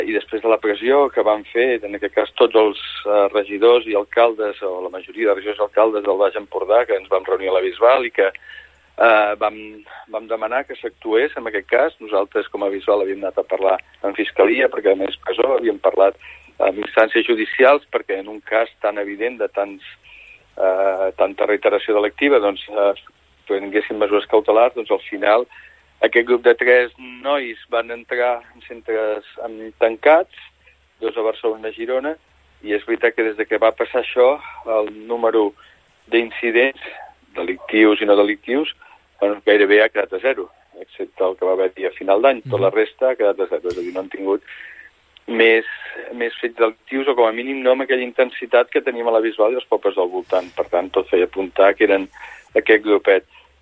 I tot i la sensació d’inseguretat que es pot percebre, l’alcalde del municipi, Òscar Aparicio, en una entrevista al Supermatí reconeix que ells només “poden fixar-se en les dades, tot i que és complicat”.